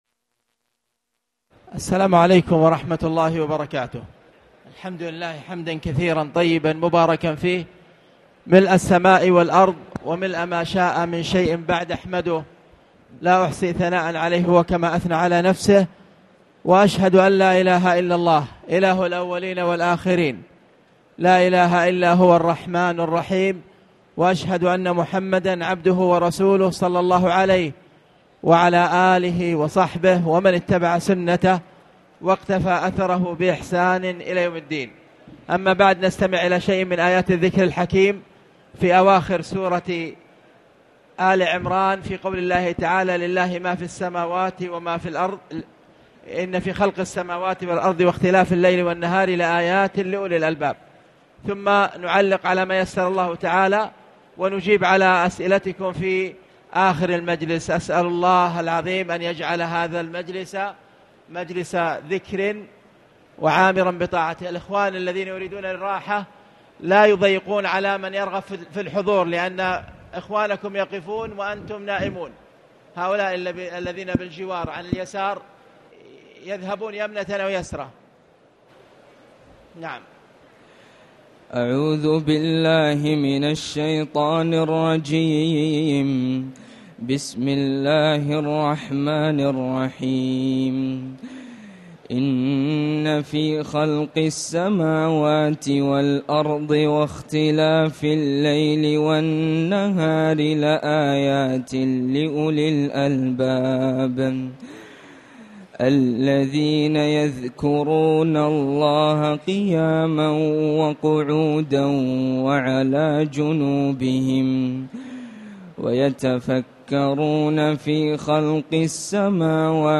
تاريخ النشر ٢٣ رمضان ١٤٣٨ هـ المكان: المسجد الحرام الشيخ